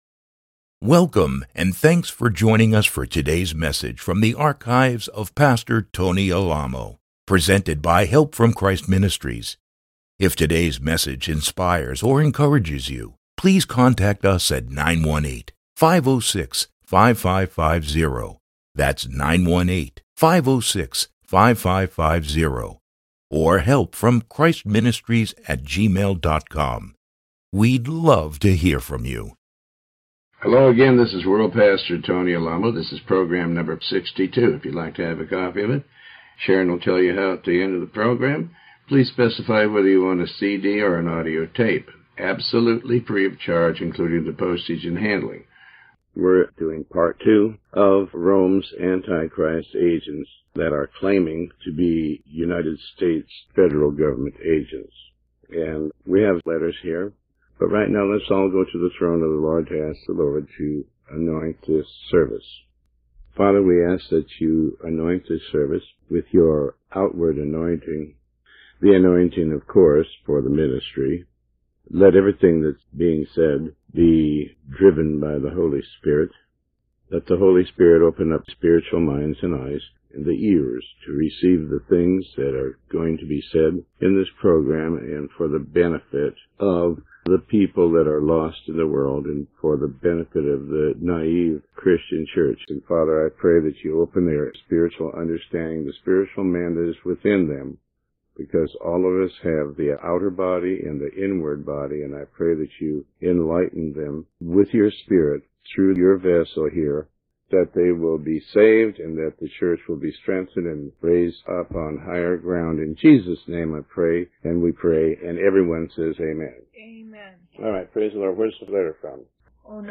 Sermon 62B